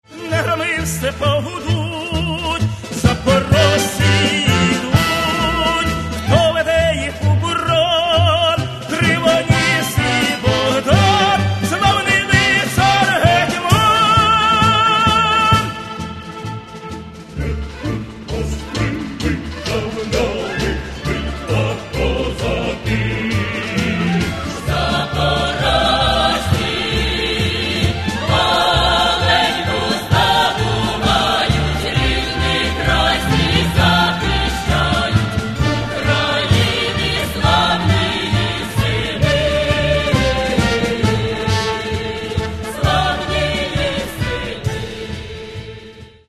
Каталог -> Народная -> Ансамбли народной музыки